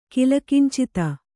♪ kilakincita